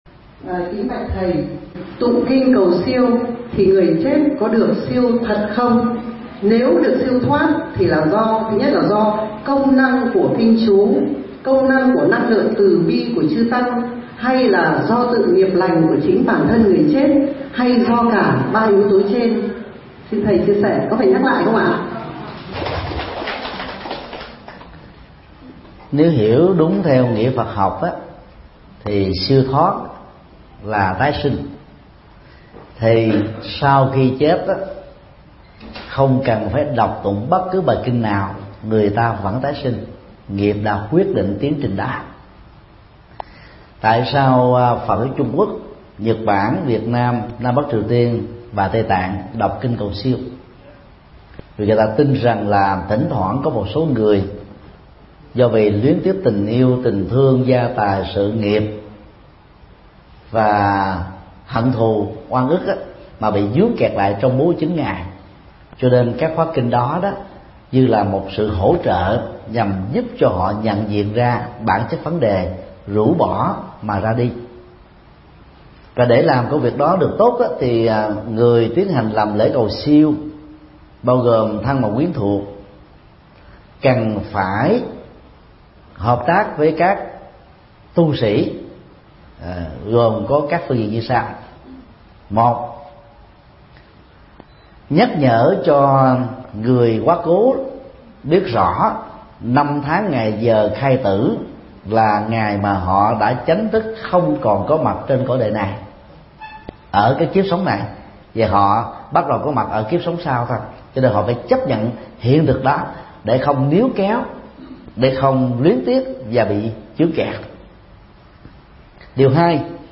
Vấn đáp: Hướng dẫn cầu siêu cho người thân đã mất